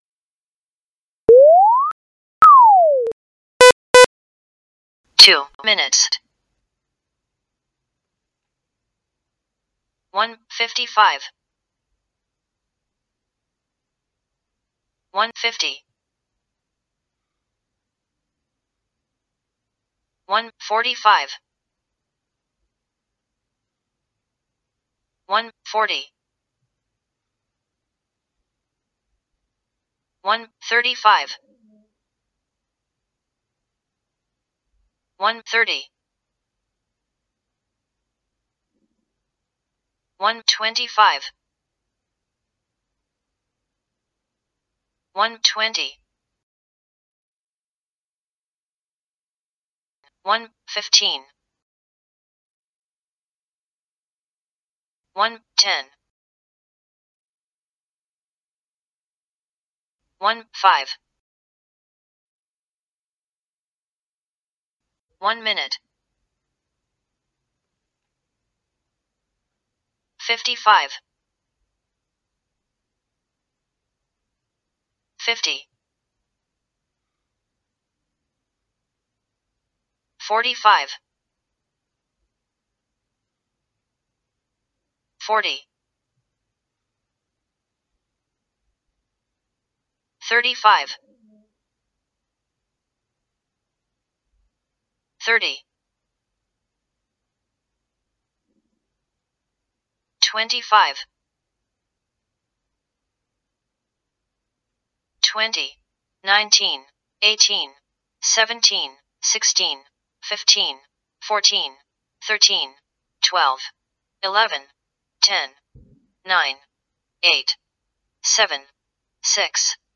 Start - countdown
Countdown2min(EN)+extra start 1min.mp3
countdown_EN_2min_plus_extra_start_1min.mp3